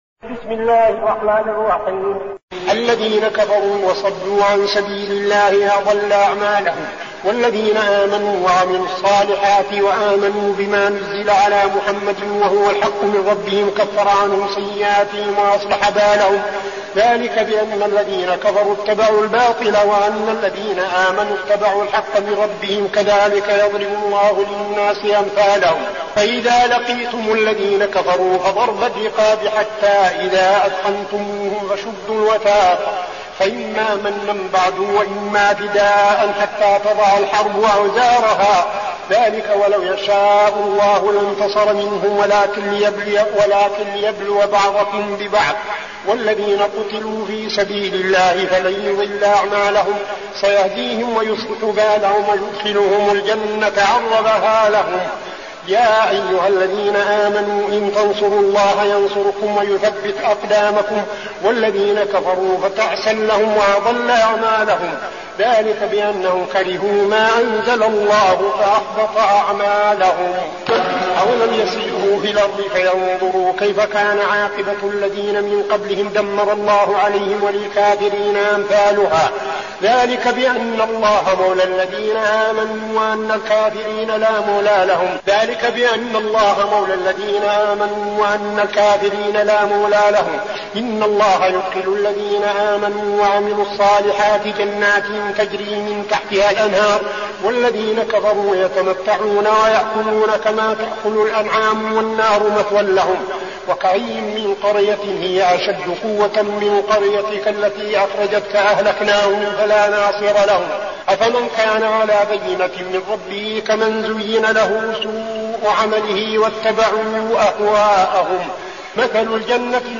المكان: المسجد النبوي الشيخ: فضيلة الشيخ عبدالعزيز بن صالح فضيلة الشيخ عبدالعزيز بن صالح محمد